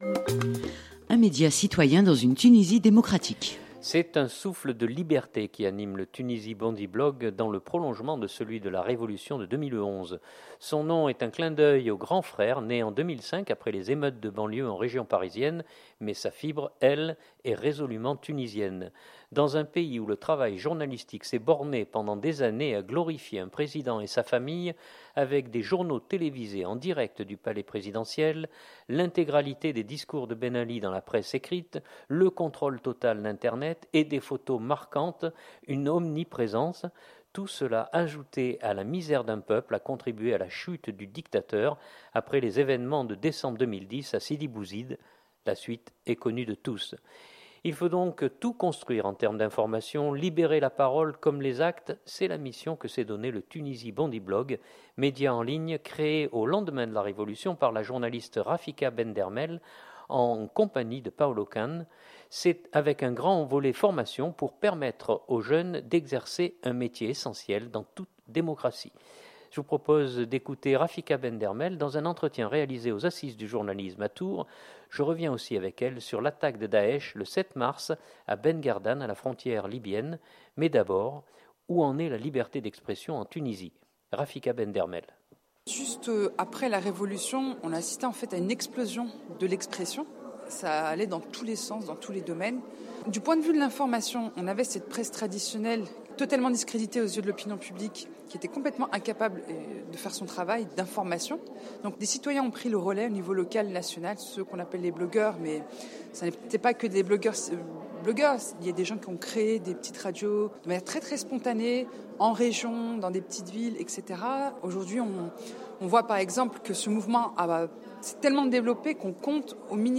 entretien réalisé aux Assises du Journalisme à Tours